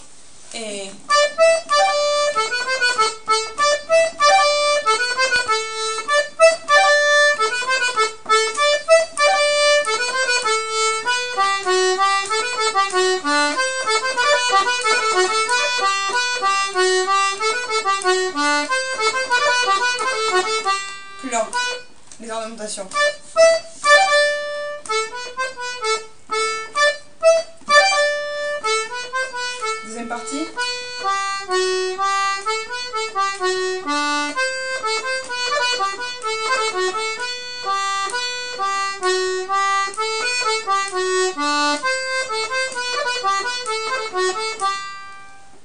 l'atelier d'accordéon diatonique
Taïtou (polka)
ornementations
taitou ornementations.mp3